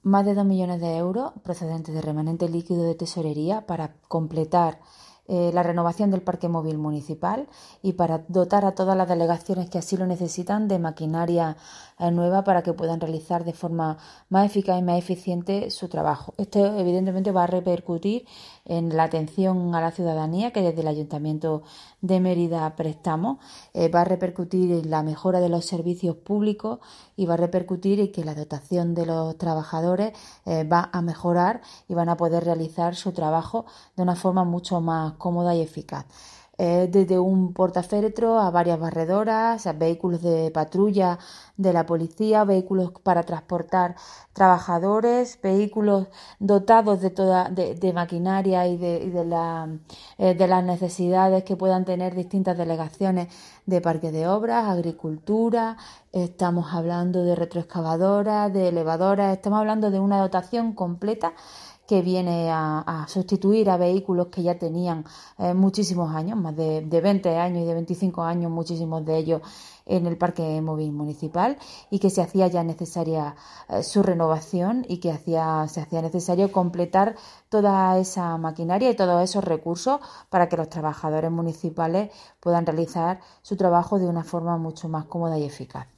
Escucha las declaraciones completas de Susana Fajardo en el siguiente enlace: